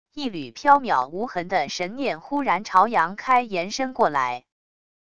一缕缥缈无痕的神念忽然朝杨开延伸过来wav音频生成系统WAV Audio Player